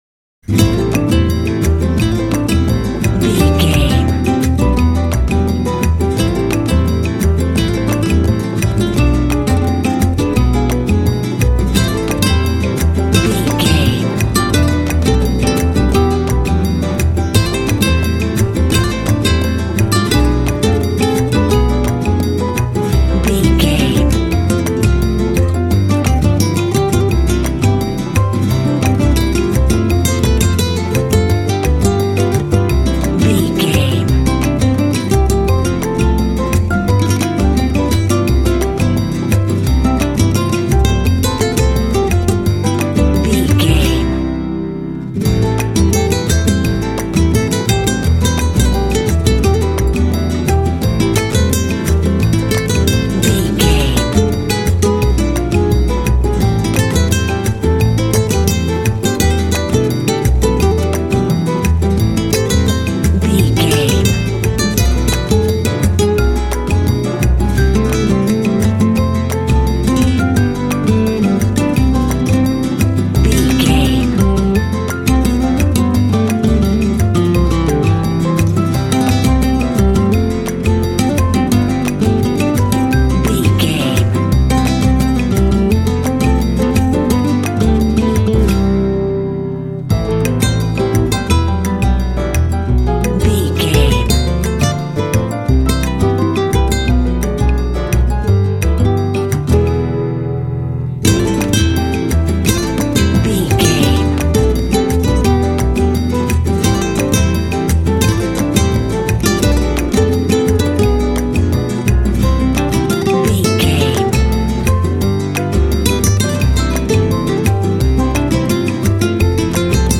Aeolian/Minor
sexy
smooth
sensual
acoustic guitar
piano
percussion
double bass
latin
flamenco
mambo
rhumba